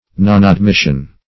Nonadmission \Non`ad*mis"sion\, n.